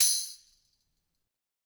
Tamb1-Hit_v2_rr1_Sum.wav